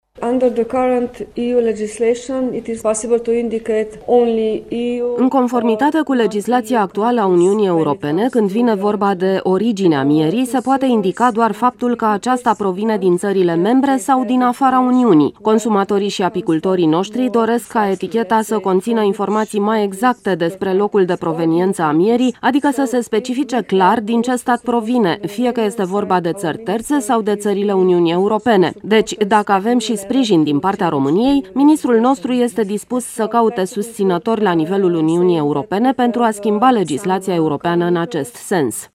România şi Slovenia vor să schimbe legislaţia din Uniunea Europeană de etichetare a mierii prin indicarea precisă a ţării de origine, indiferent dacă aceasta provine de la ţări un UE sau state terţe. Acest lucru a fost afirmat sâmbătă de Tanja Strnisa, secretar de stat din cadrul Ministerului Agriculturii, Pădurilor şi Alimentaţiei din Republica Slovenia, la conferinţa ‘Apicultura în context european şi mondial’.